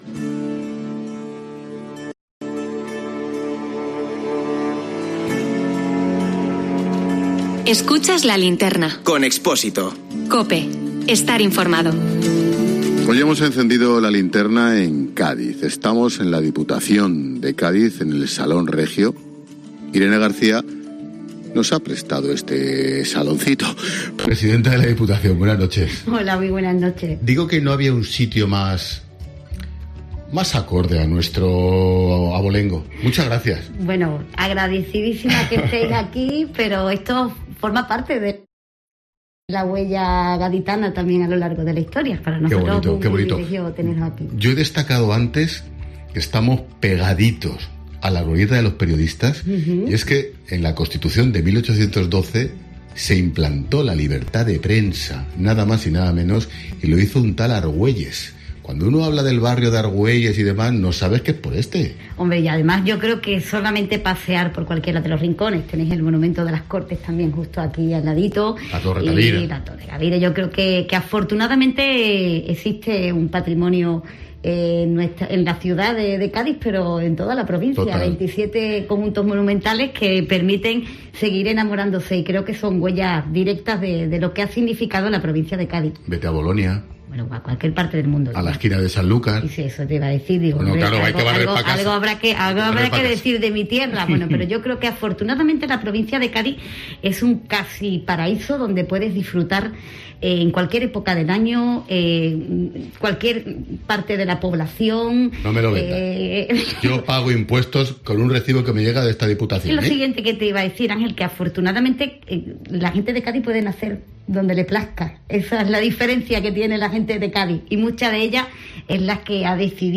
Irene García, presidenta de la Diputación de Cádiz, ha sido protagonista en La Linterna de Ángel Expósito. Un programa emitido desde el Salón Regio del palacio provincial.